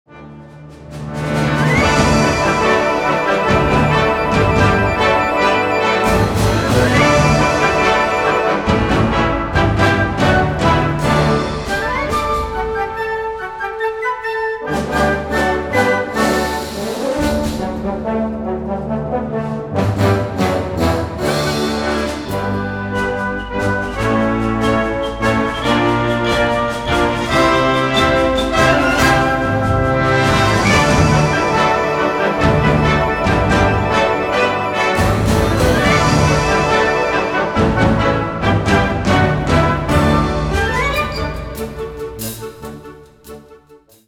Gattung: Burleske für Blasorchester
Besetzung: Blasorchester